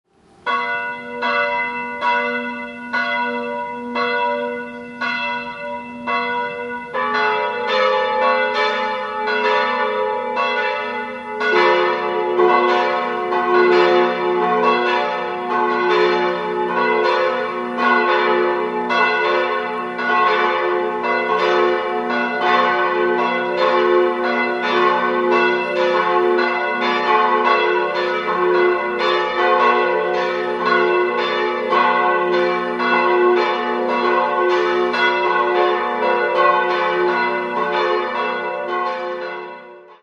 3-stimmiges TeDeum-Geläute: fis'-a'-h'
920 kg 135 cm 1949 Bochumer Verein für Gussstahlfabrikation
Die Kröpfung ist bei diesem Geläute in V12-Rippe leider nicht zu überhören.